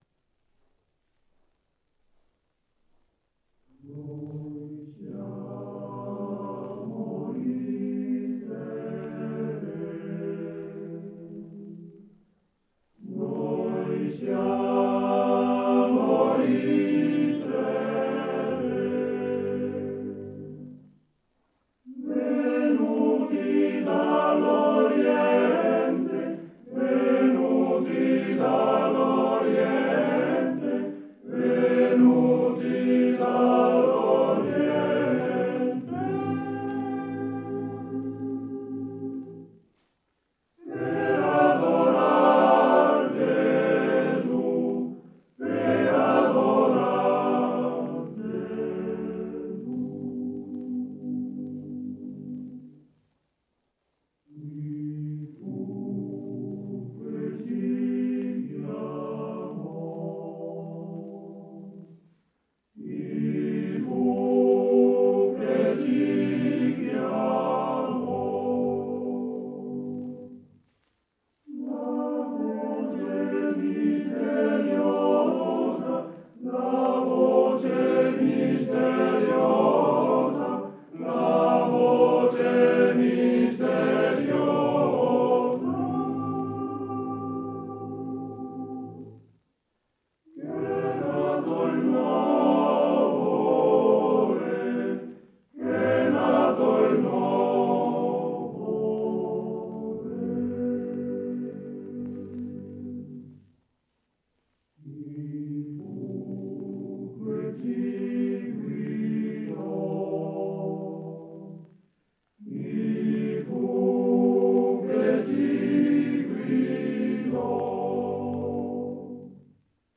Arrangiatore: Pigarelli, Luigi
Esecutore: Coro della SAT